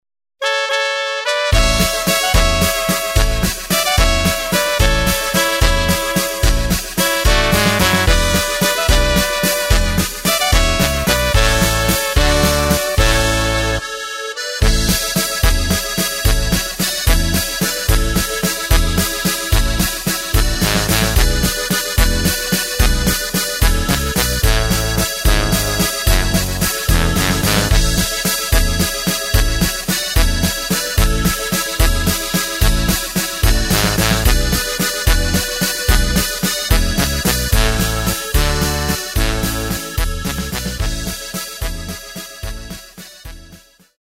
Takt:          3/4
Tempo:         220.00
Tonart:            A
Walzer aus dem Jahr 1983!
mp3 Playback mit Lyrics